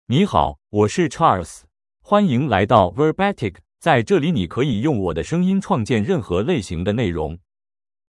Charles — Male Chinese (Mandarin, Simplified) AI Voice | TTS, Voice Cloning & Video | Verbatik AI
Charles is a male AI voice for Chinese (Mandarin, Simplified).
Voice sample
Charles delivers clear pronunciation with authentic Mandarin, Simplified Chinese intonation, making your content sound professionally produced.